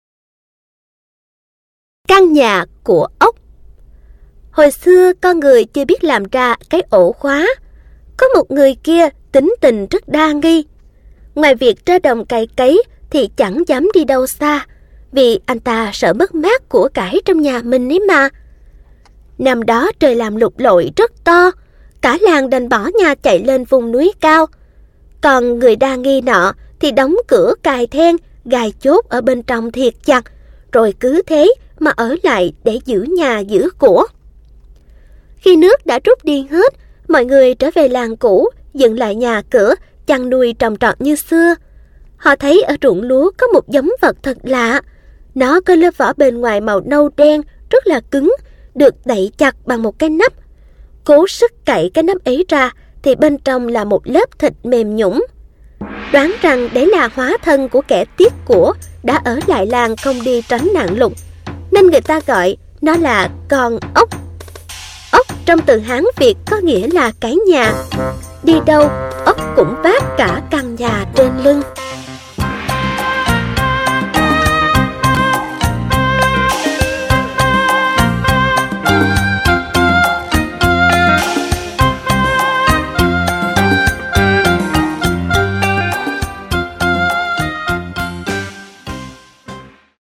Sách nói | Xóm đồ chới